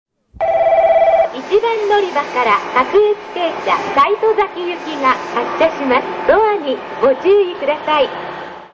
発車放送（普通・西戸崎）